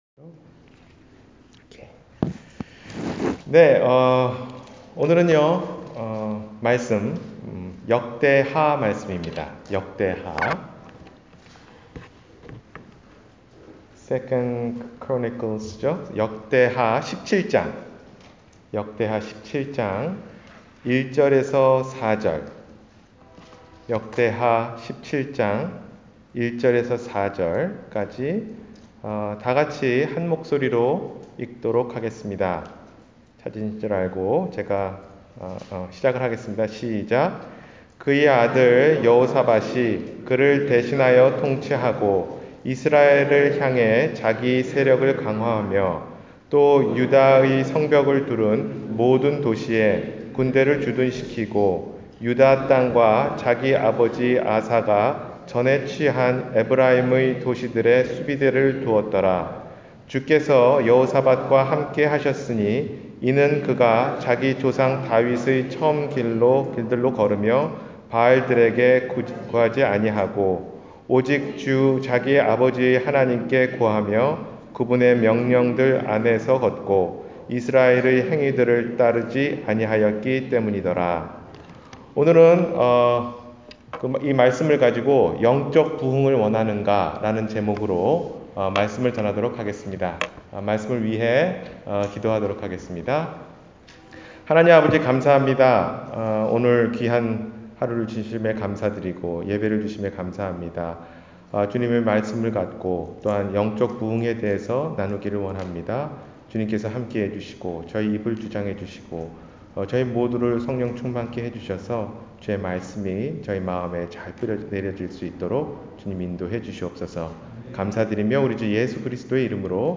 – 주일설교